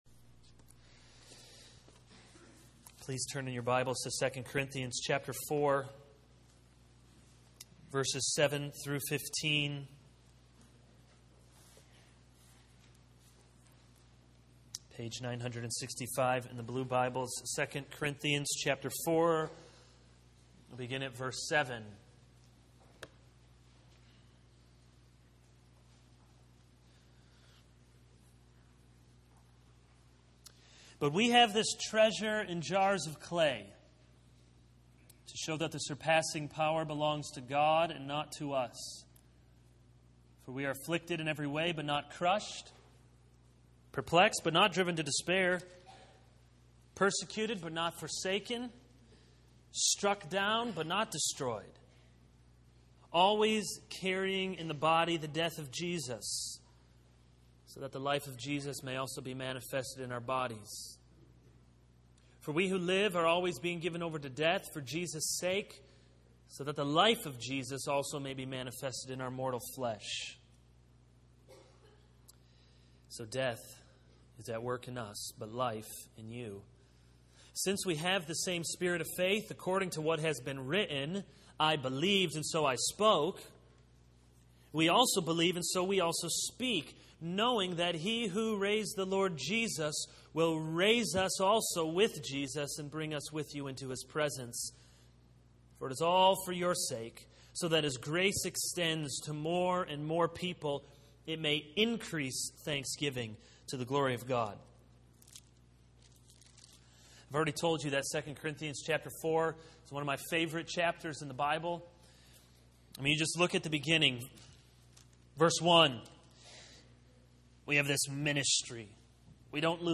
This is a sermon on 2 Corinthians 4:7-15.